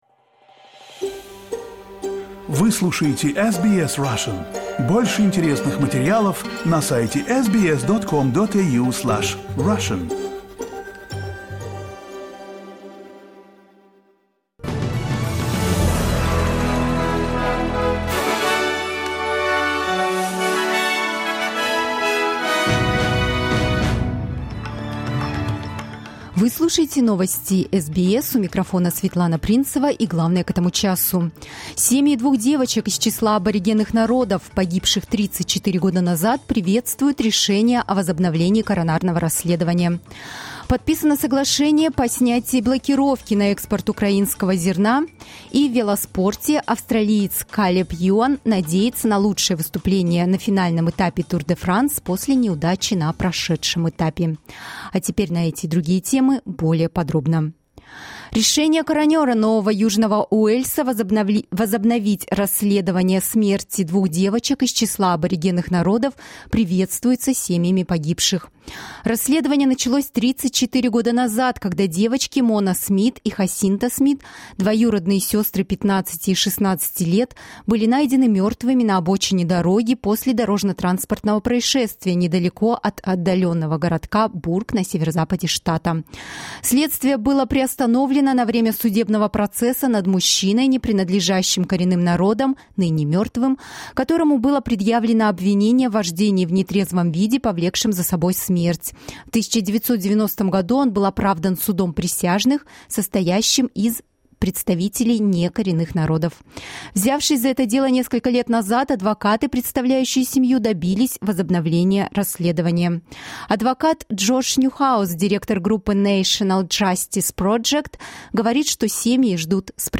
Новости SBS на русском языке - 23.07.2022